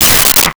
Space Gun 02
Space Gun 02.wav